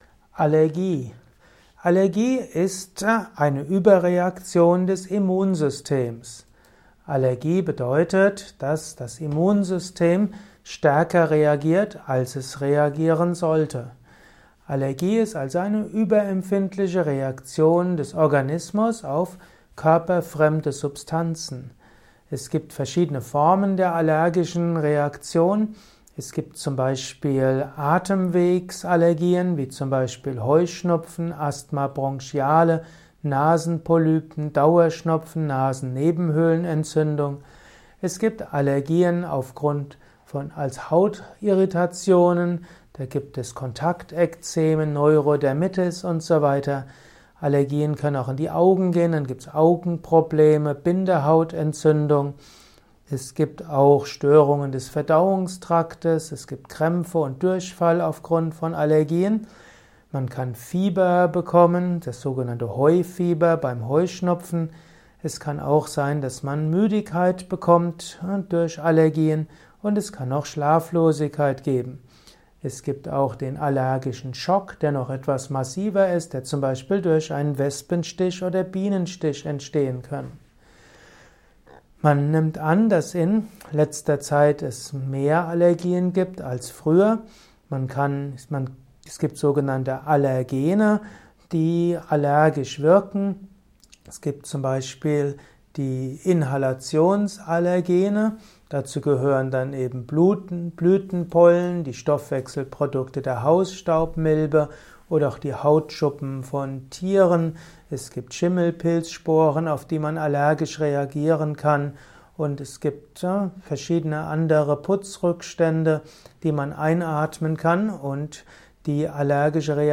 Dies ist nur ein Kurzvortrag - magst du das ergänzen?